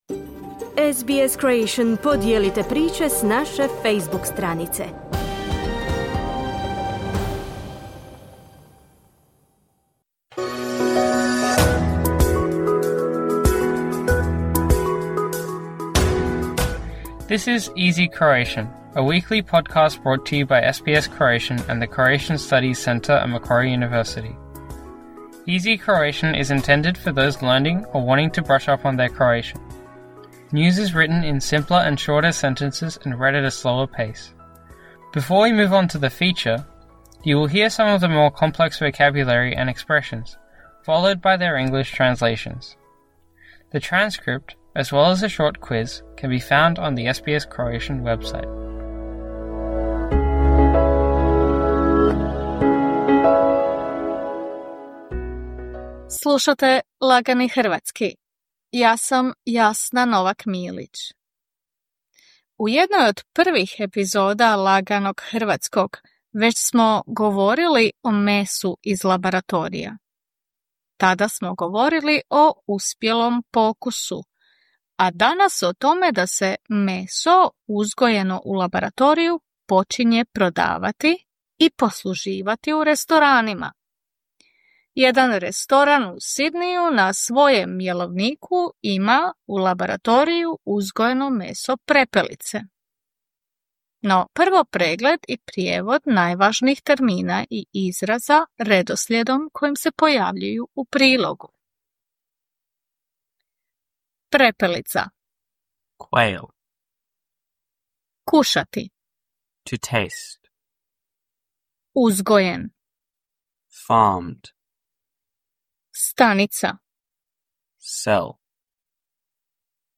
“Easy Croatian” is intended for those who want to brush up on their Croatian. News is written in simpler and shorter sentences and read at a slower pace.